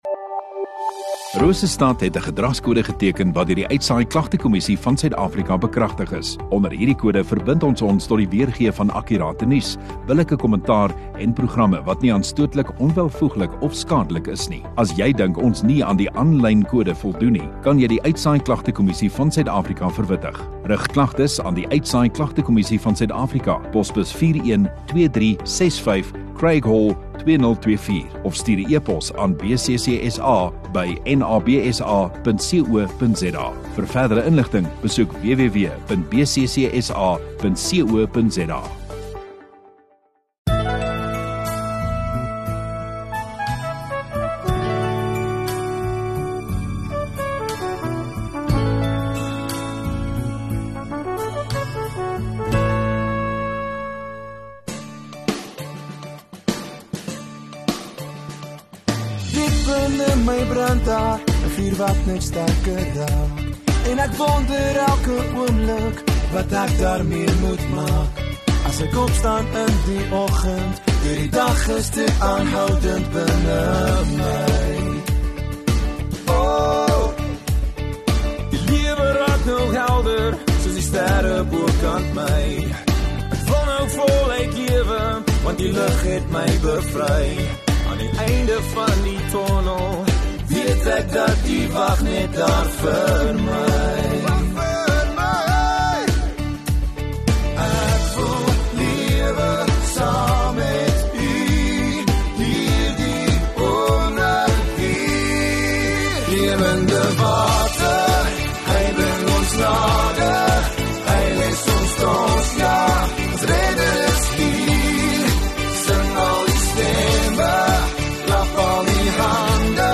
6 Jul Saterdag Oggenddiens